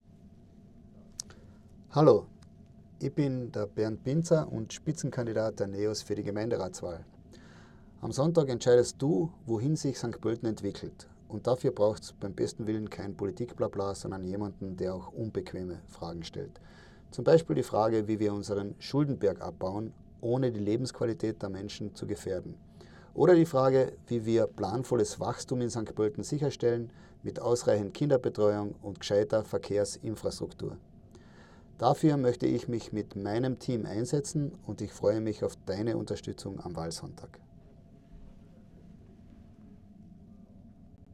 Sprachnachricht-Wahlaufruf als File